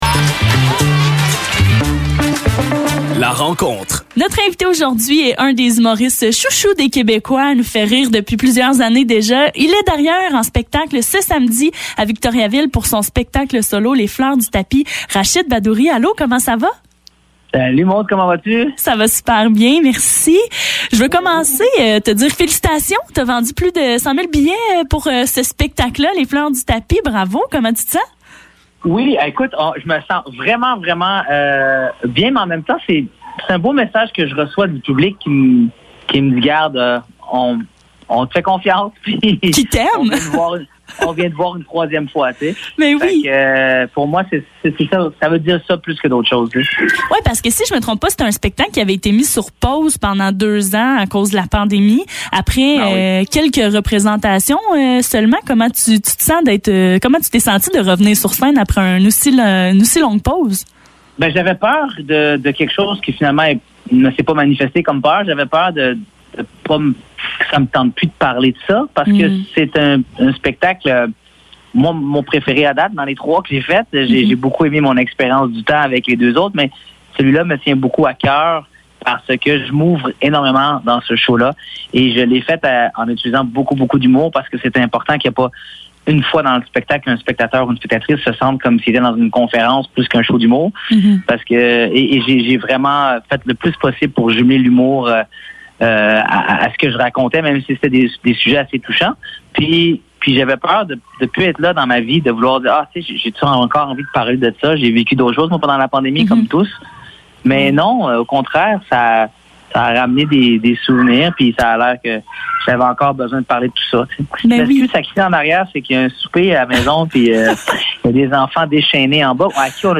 Entrevue avec Rachid Badouri